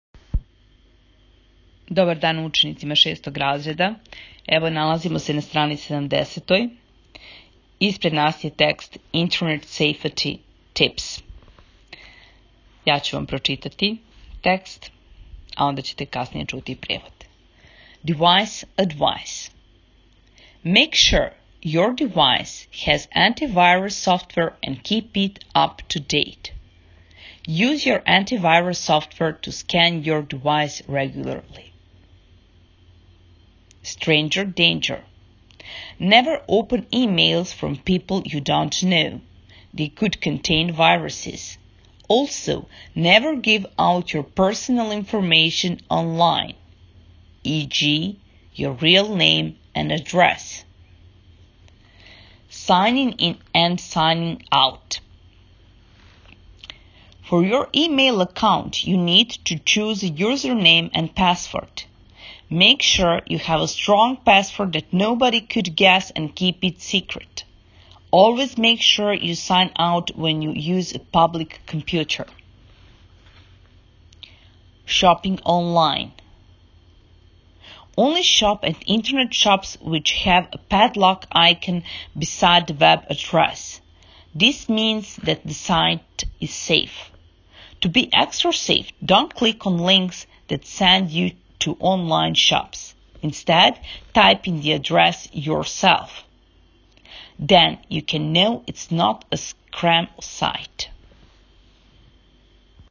Читање:
6.raz_.čitanje-Internet-Safety-Tips.m4a